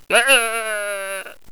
sheep_die2.wav